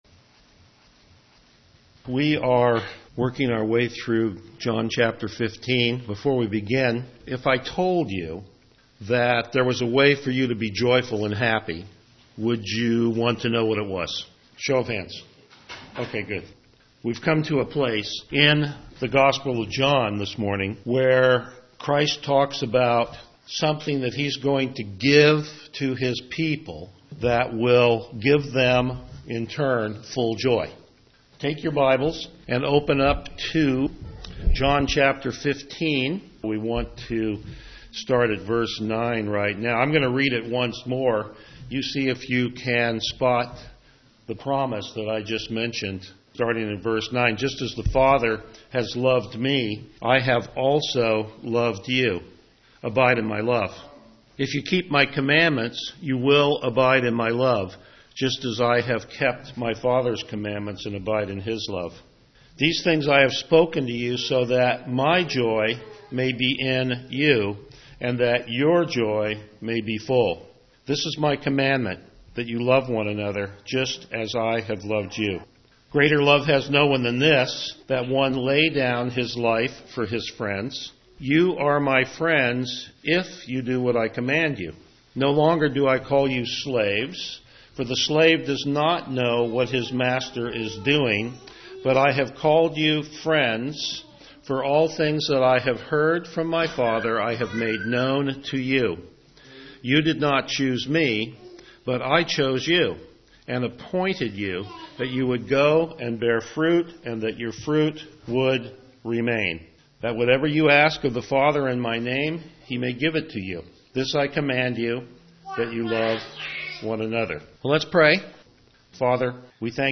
Passage: John 15:9-17 Service Type: Morning Worship
Verse By Verse Exposition